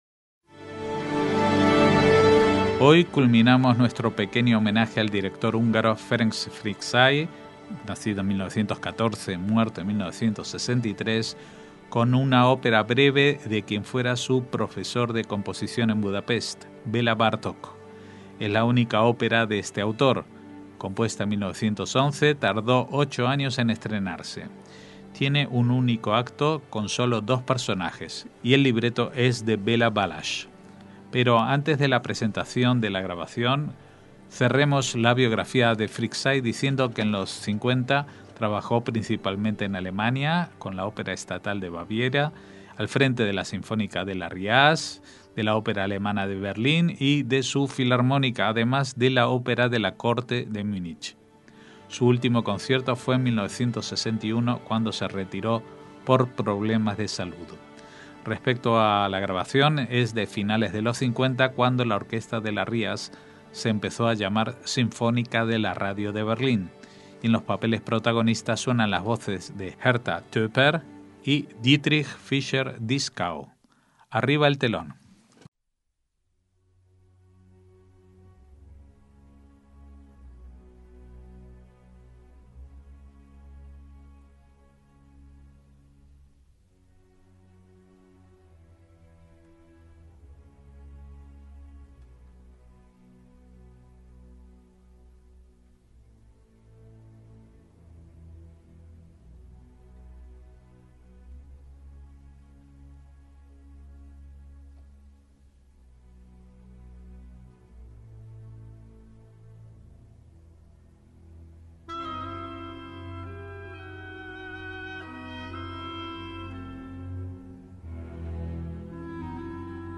ÓPERA JUDAICA - Hoy culminamos nuestro pequeño homenaje al director húngaro Ferenc Fricsay (1914 –1963) con una ópera breve de quien fuera su profesor de composición en Budapest, Bela Bartok. Es la única ópera de este autor.
Tiene un único acto con sólo dos personajes y el libreto es de Bela Balasz.